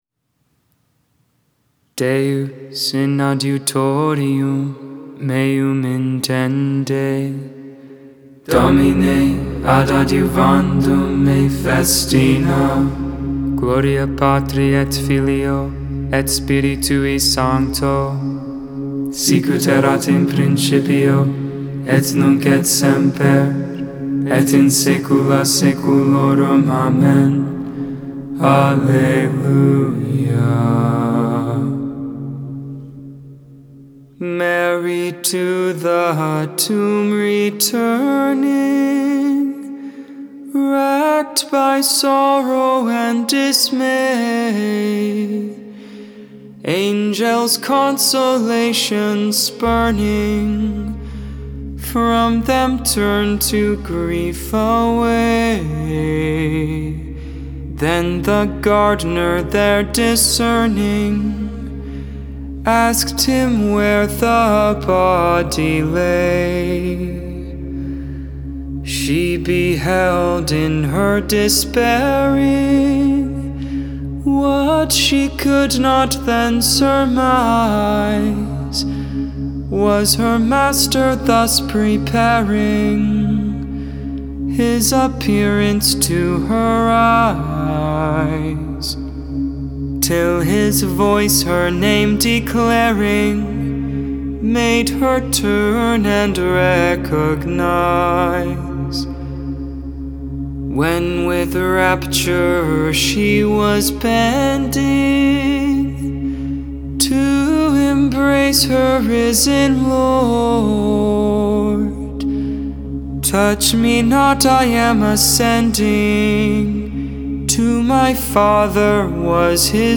7.22.21 Vespers, Thursday Evening Prayer